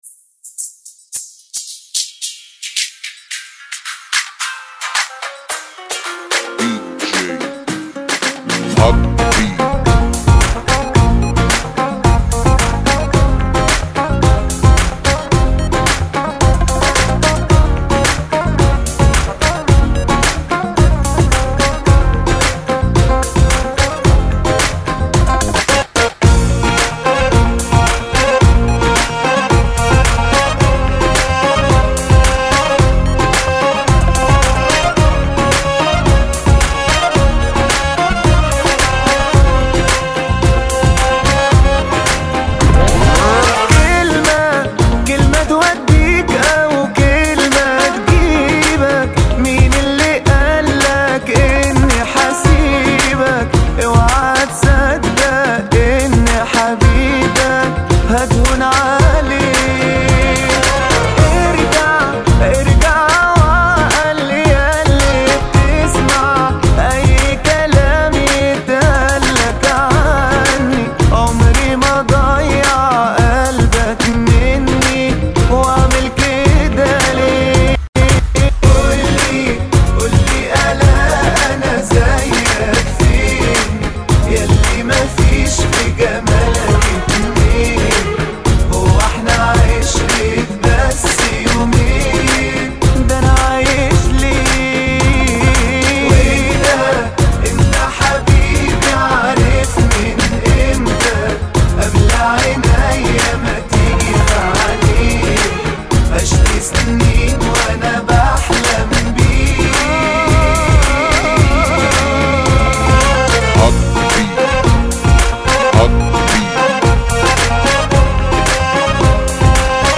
Rmx